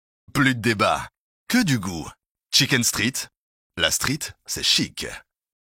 PUB CAN 2025 beIN SPORT - Chicken Street
- Basse